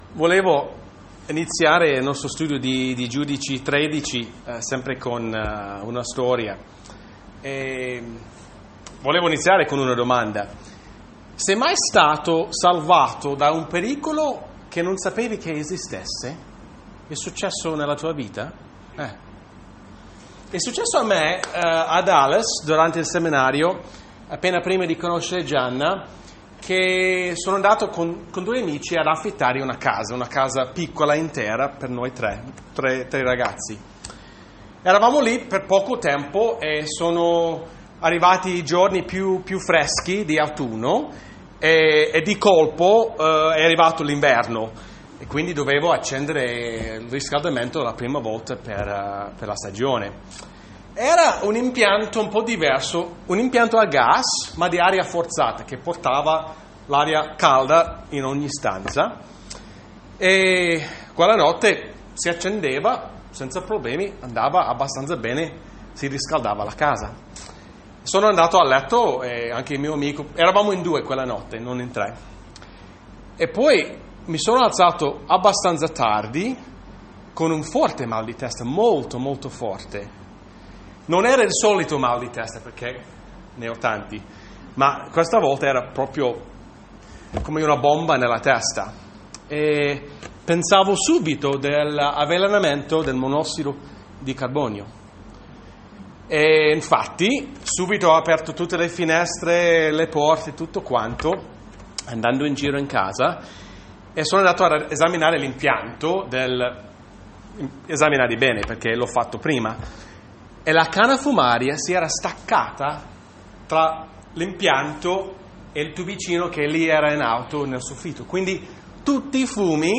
Bible Text: Giudici 13 | Predicatore/Preacher